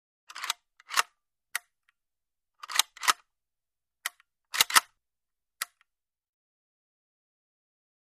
Mini-14: Slide Pull / Dry Fire; Series Of Slide-dry Fires Of A Mini 14 Automatic Rifle. Three Pull Backs Of Slide To Chamber Round With Resulting Dry Fire. Close Perspective. Guns.